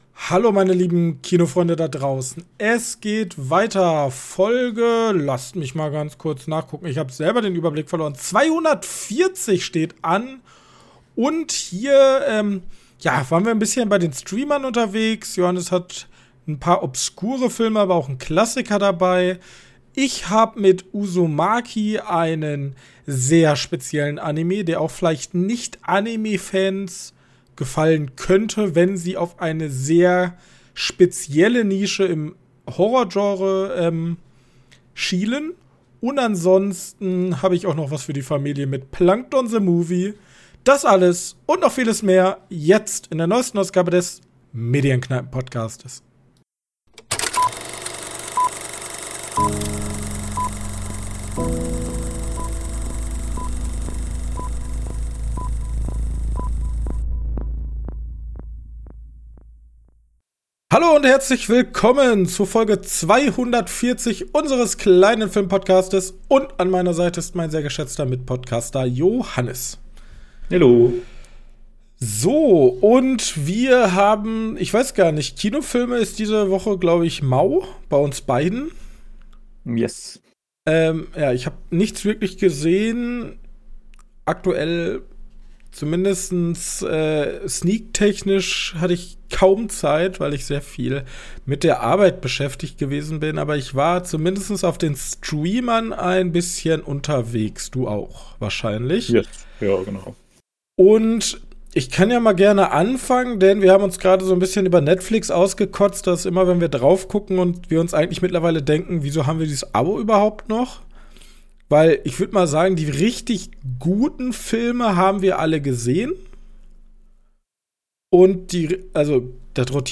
Seht unsere Gespräche also lieber als ein Kneipengespräch unter Freunden an und seid nicht überrascht, wenn wir vom Höcksken aufs Stöcksken kommen.